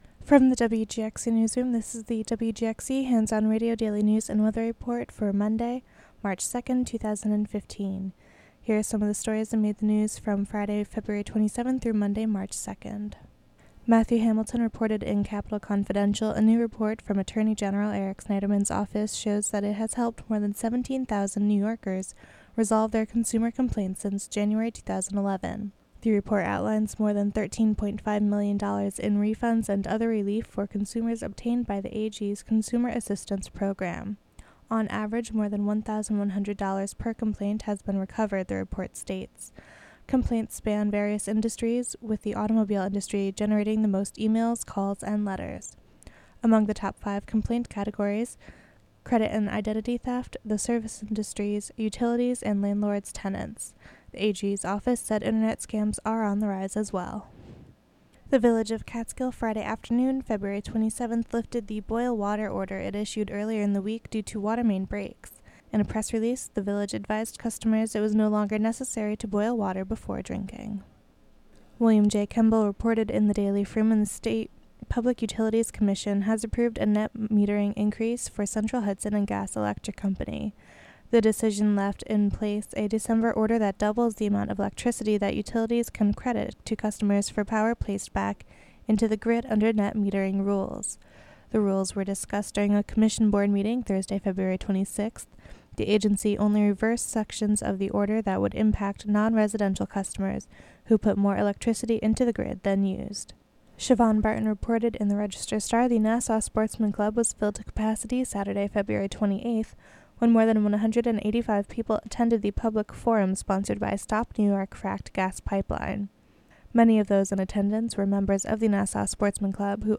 Local news and weather for Monday, March 2, 2015.